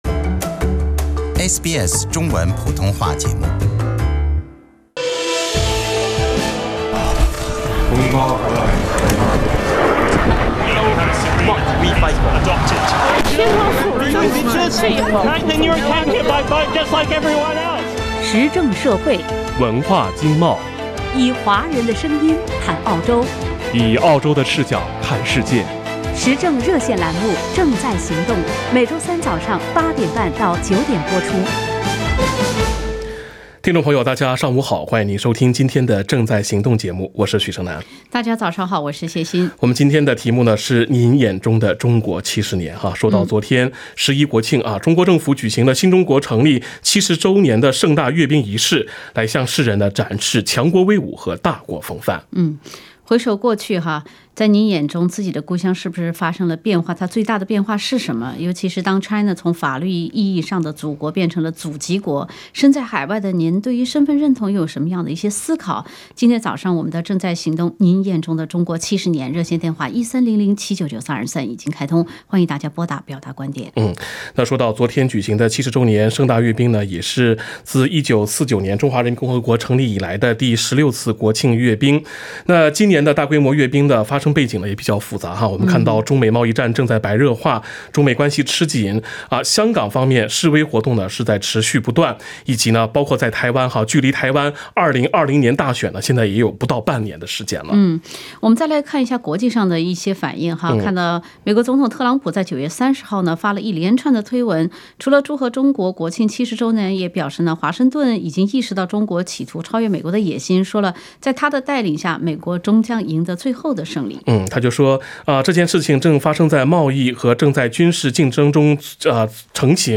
时政热线节目《正在行动》逢周三上午8点30分至9点播出。